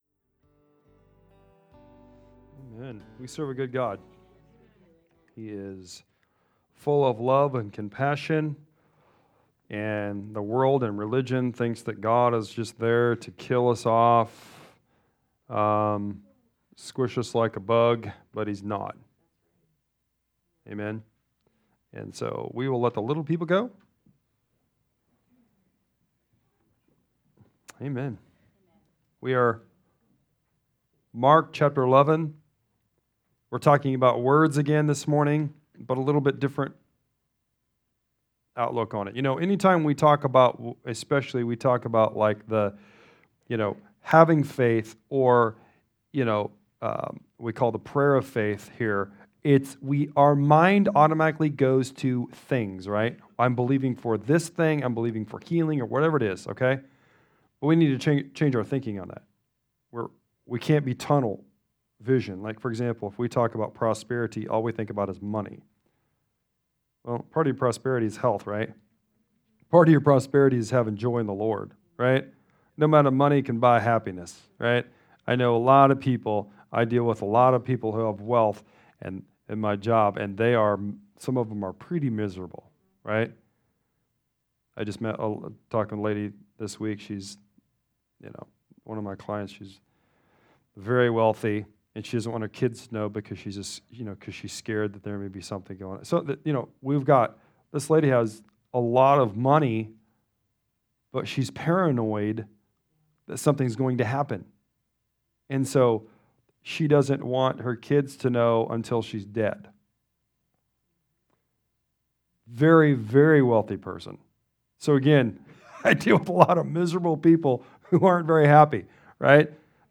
A collection of sermons/pastoral messages from 2018-2022.